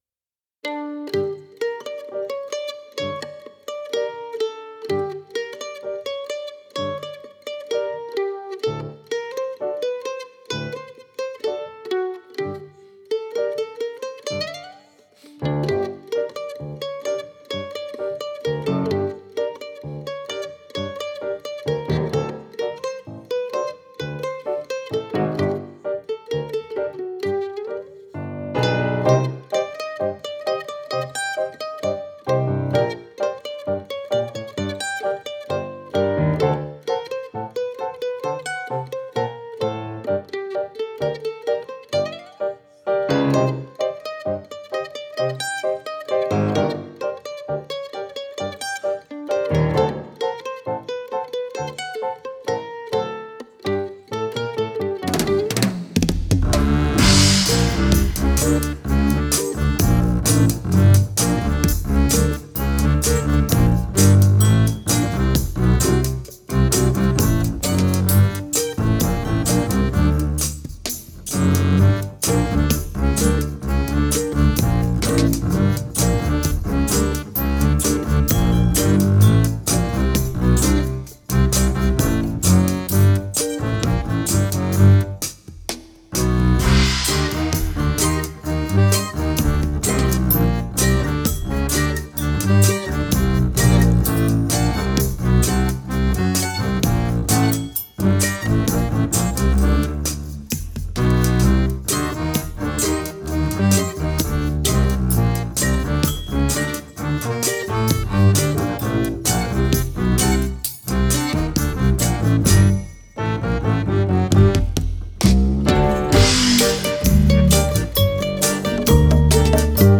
Genre: Jazz, World, Accordion